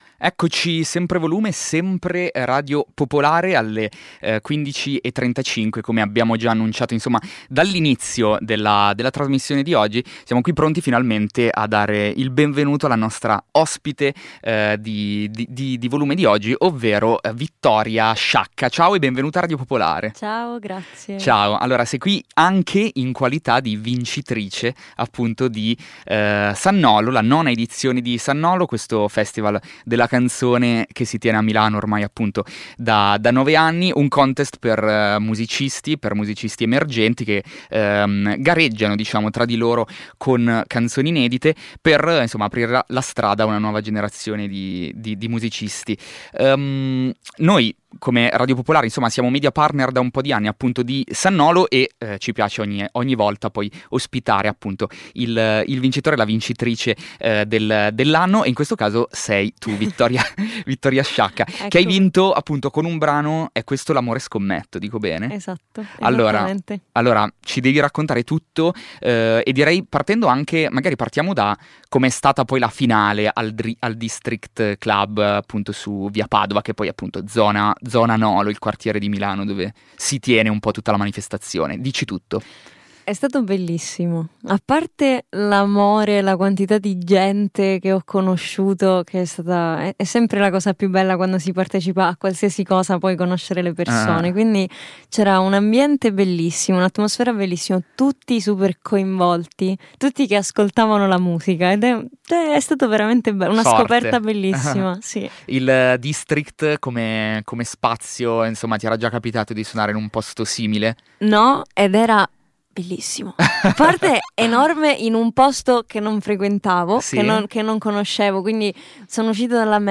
Anche nell’edizione 2026, il Festival ha messo in palio una serie di premi tecnici destinati ai vincitori, tra cui un’intervista con MiniLive negli studi di Radio Popolare - che da anni è media partner dell’evento.